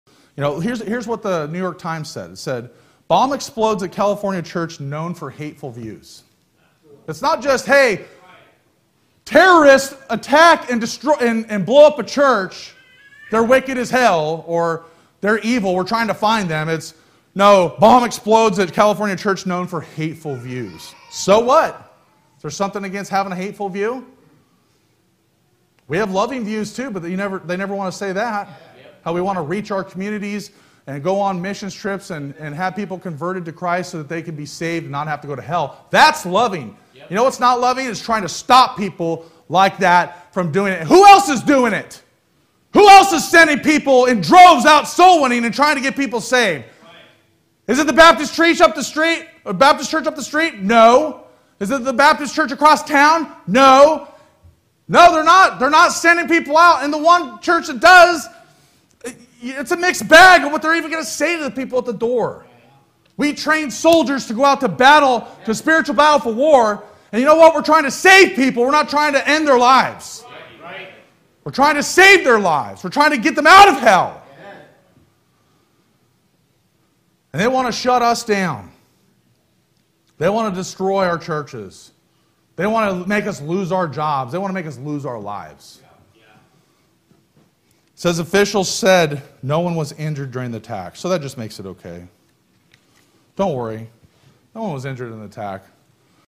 Terrorist Attack Baptist Church News Coverage
Terrorist_Attack_Baptist_Church_News_Coverage.mp3